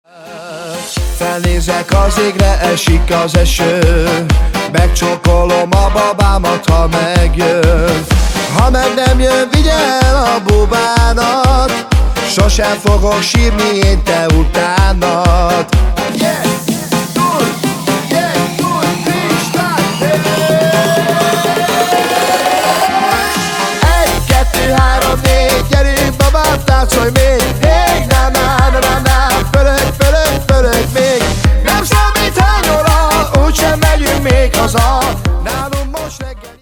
Minőség: 320 kbps 44.1 kHz Stereo